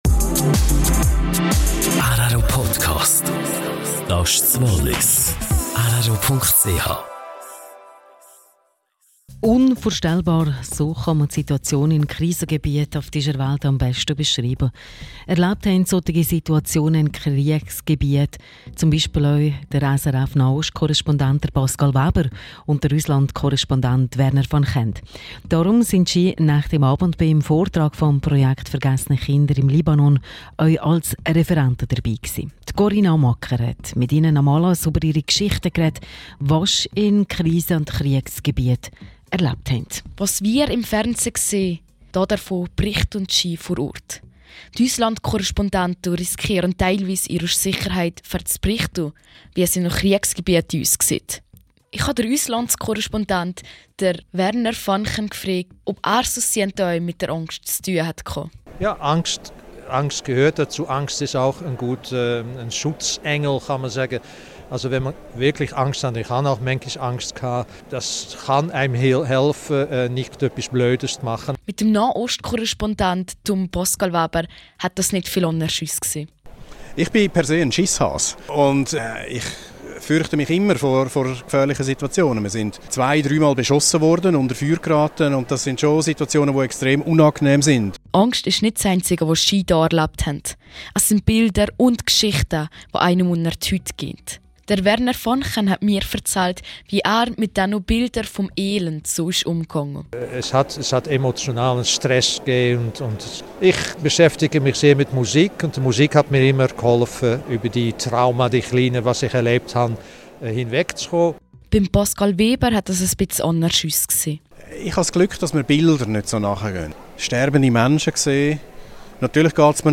(Quelle: rro) Hilfe durch Bildung: Interview mit Korrespondenten in Kriegsgebieten.